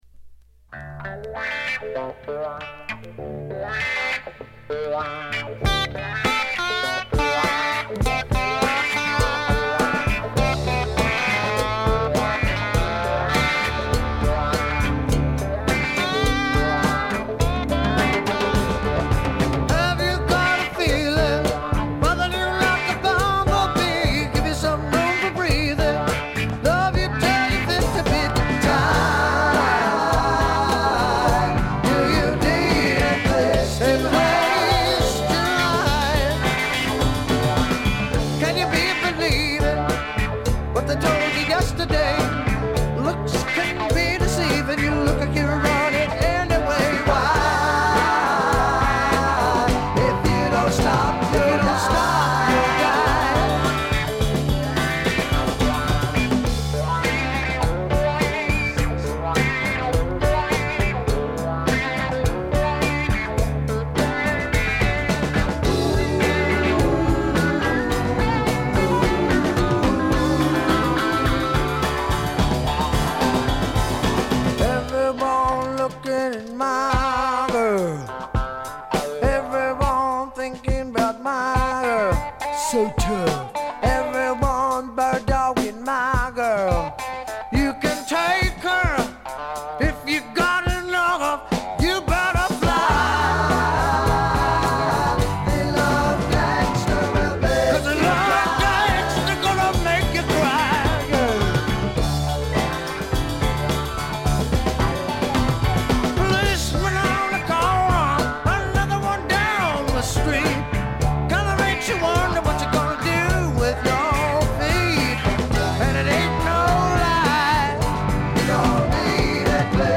部分試聴ですがわずかなノイズ感のみ。
試聴曲は現品からの取り込み音源です。